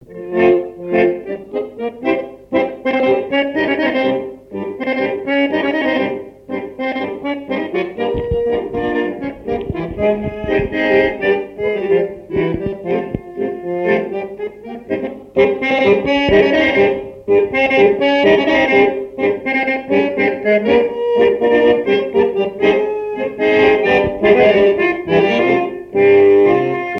danse : tango musette
Genre strophique
Pièce musicale inédite